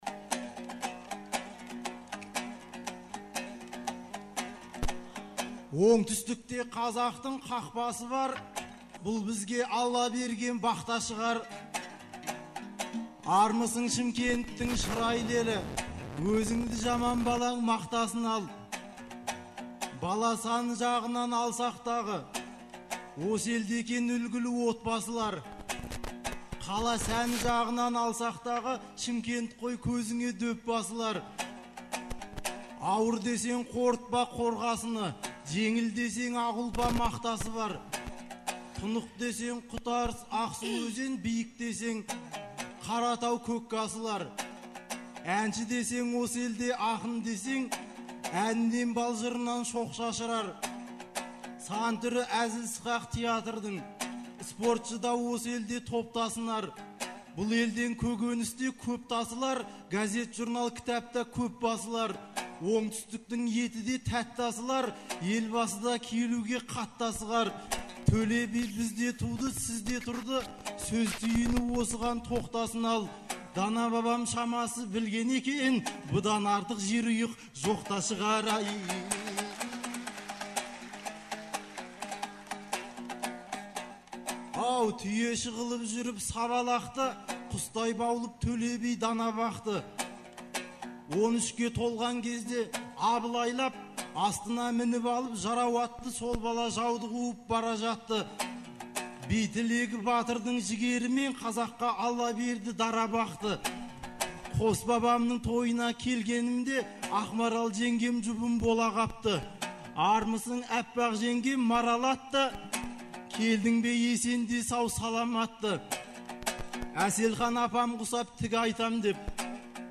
Наурыздың 15-сі мен 16-сы күндері Шымкент қаласында республикалық «Наурыз» айтысы өтті. 2004 жылдан бері тұрақты өтіп келе жатқан бұл айтыс биылғы жылы Төле бидің 350 және Абылай ханның 300 жылдықтарына арналды.